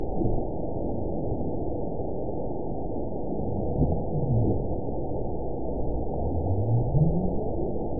event 918003 date 04/26/23 time 14:56:31 GMT (2 years ago) score 9.45 location TSS-AB03 detected by nrw target species NRW annotations +NRW Spectrogram: Frequency (kHz) vs. Time (s) audio not available .wav